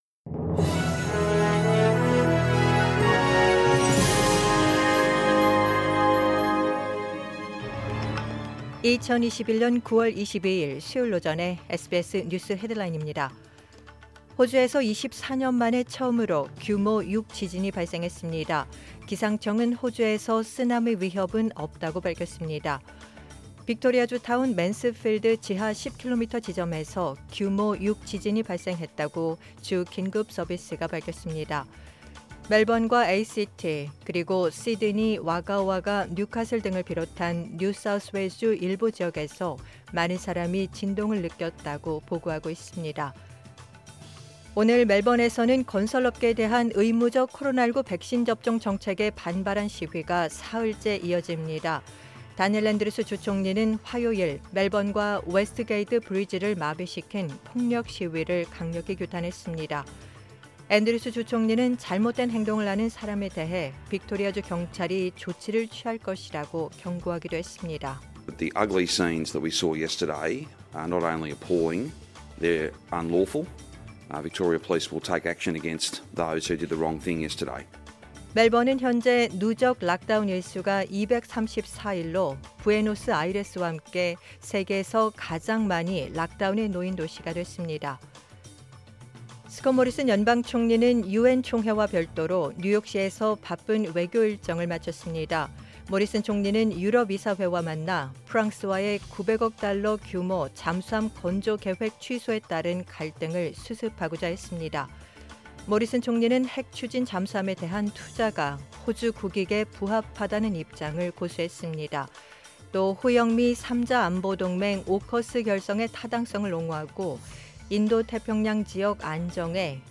2021년 9월 22일 수요일 오전의 SBS 뉴스 헤드라인입니다.